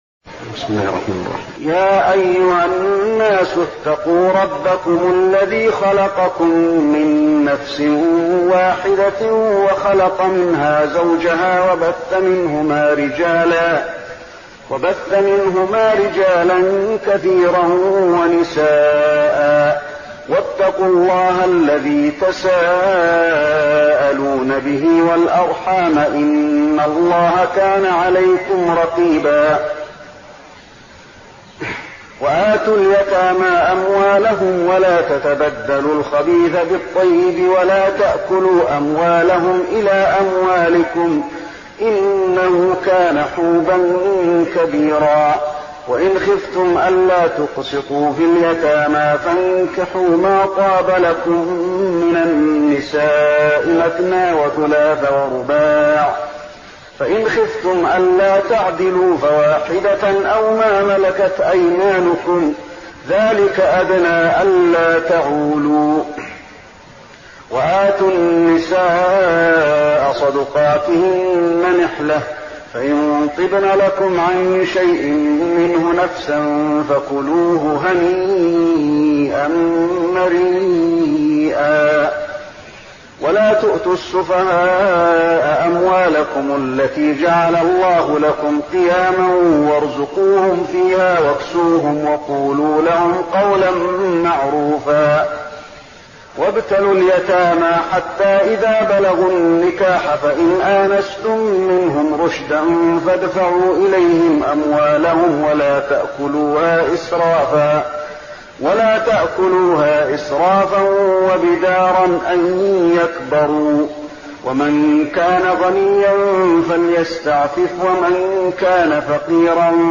تهجد رمضان 1415هـ من سورة النساء (1-11) Tahajjud Ramadan 1415H from Surah An-Nisaa > تراويح الحرم النبوي عام 1415 🕌 > التراويح - تلاوات الحرمين